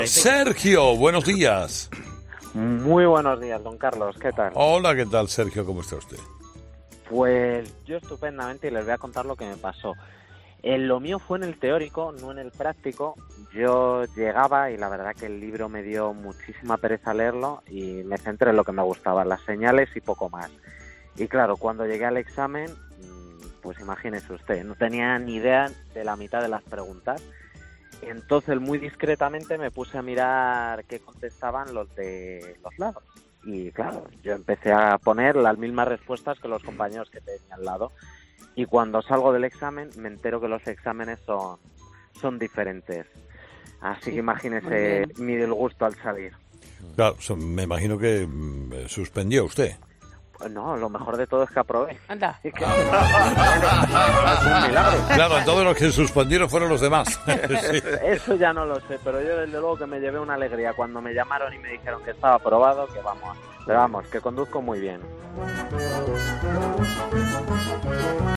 Este miércoles los oyentes han recordado las dificultades con las que se encontraron cuando trataron de sacarse el permiso de circulación